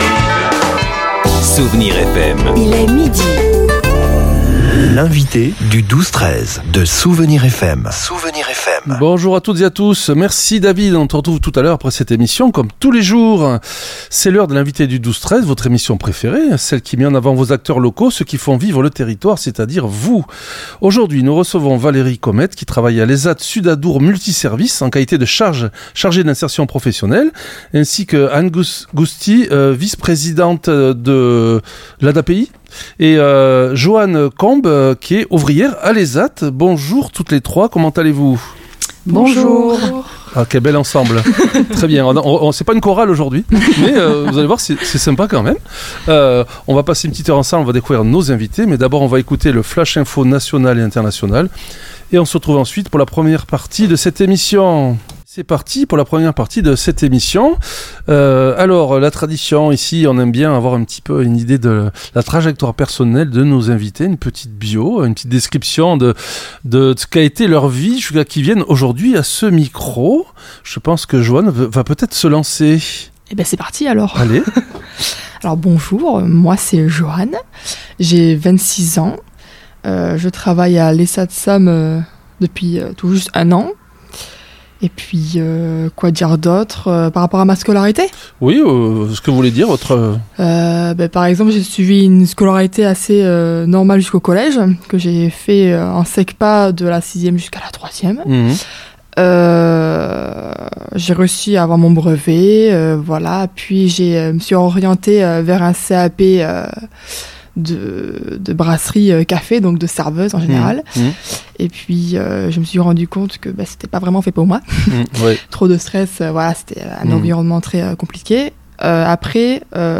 L'entretien a mis en lumière le parcours d'insertion personnalisé qui permet à chaque ouvrier